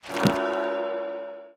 Minecraft Version Minecraft Version 1.21.5 Latest Release | Latest Snapshot 1.21.5 / assets / minecraft / sounds / block / enchantment_table / enchant3.ogg Compare With Compare With Latest Release | Latest Snapshot